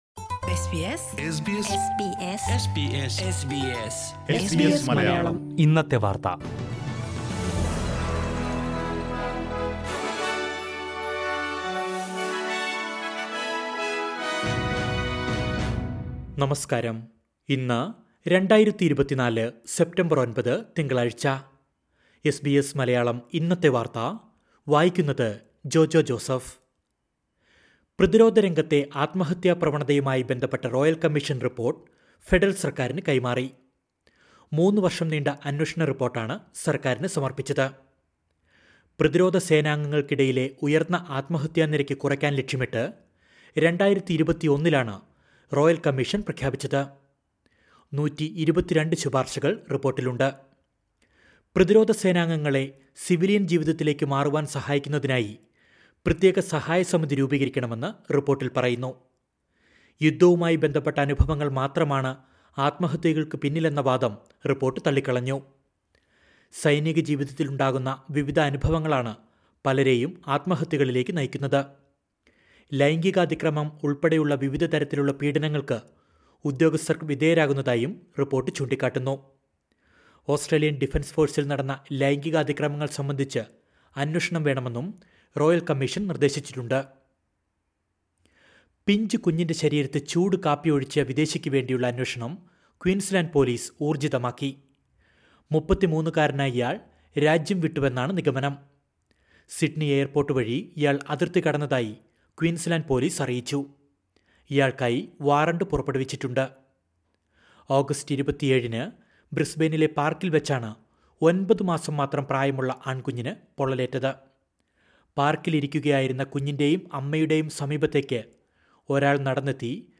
2024 സെപ്റ്റംബർ 9ലെ ഓസ്‌ട്രേലിയയിലെ ഏറ്റവും പ്രധാന വാര്‍ത്തകള്‍ കേള്‍ക്കാം...